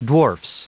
Prononciation du mot dwarfs en anglais (fichier audio)
Prononciation du mot : dwarfs